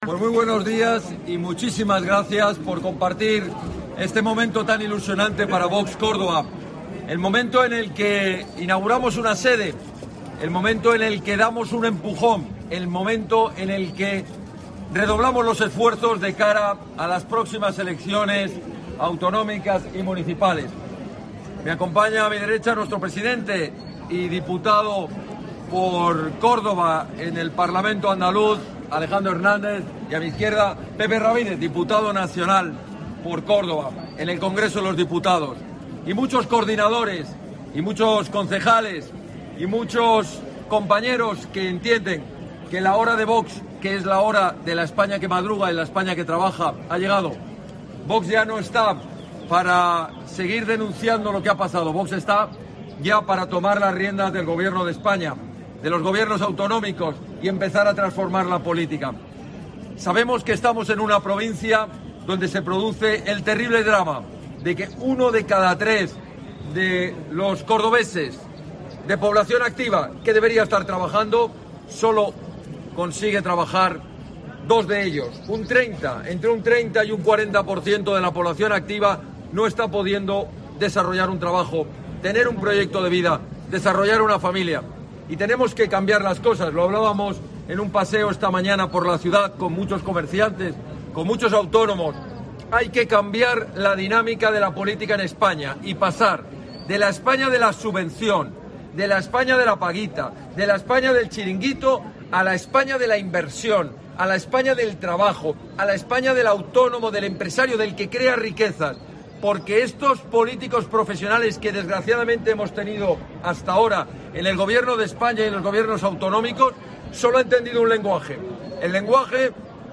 Smith pide elecciones en Andalucía en la inauguración de la sede de Vox en Córdoba